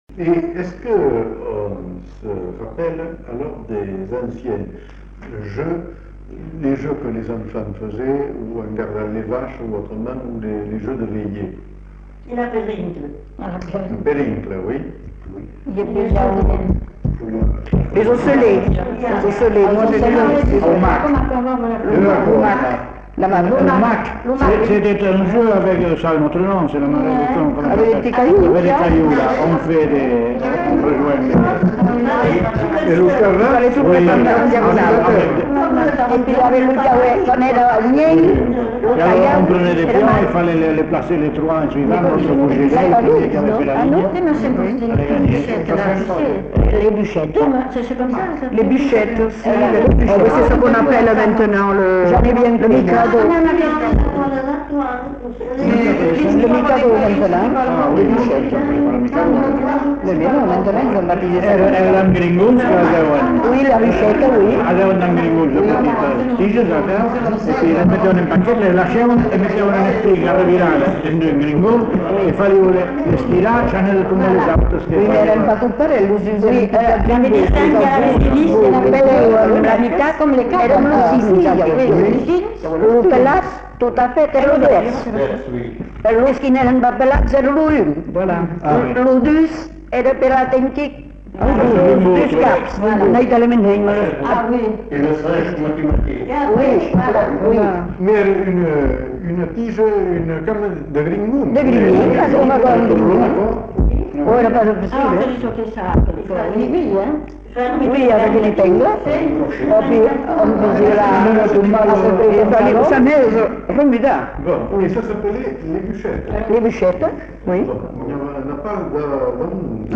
Lieu : Uzeste
Genre : témoignage thématique